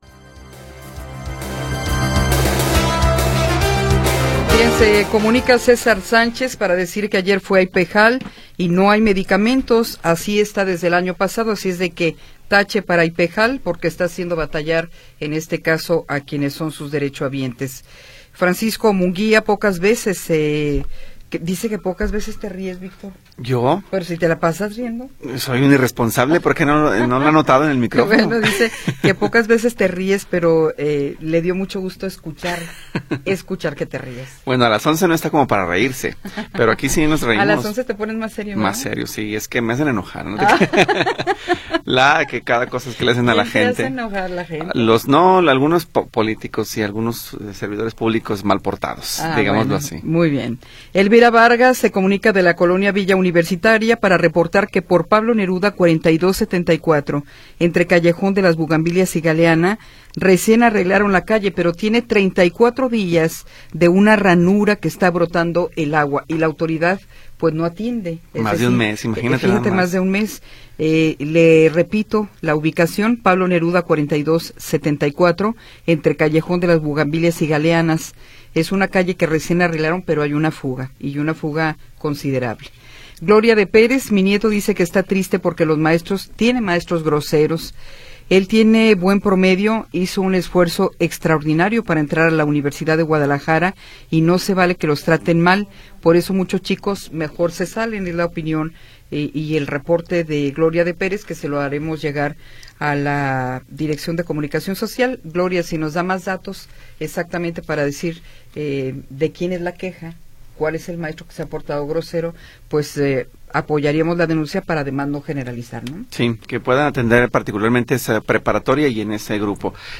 Tercera hora del programa transmitido el 12 de Febrero de 2026.